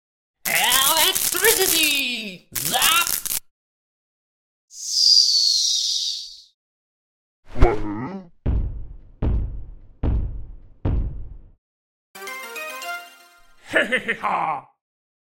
Electro Wizard sound in real sound effects free download
Electro Wizard sound in real life